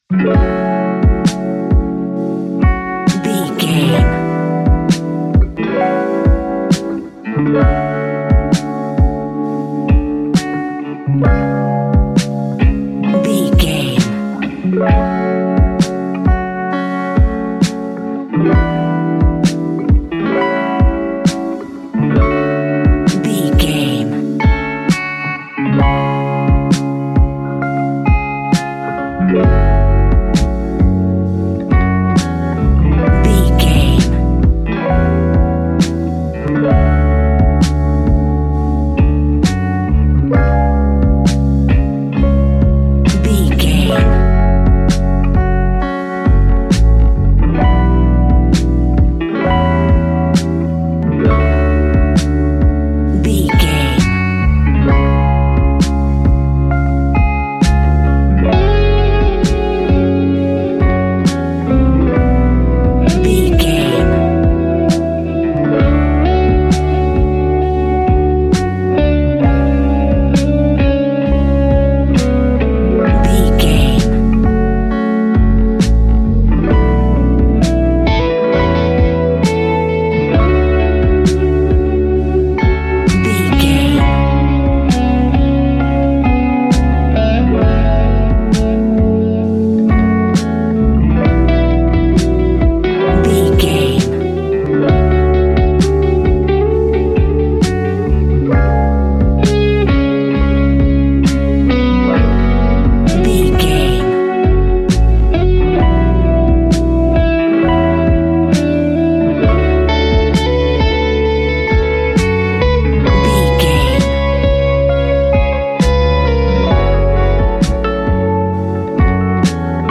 Ionian/Major
F♯
laid back
Lounge
sparse
chilled electronica
ambient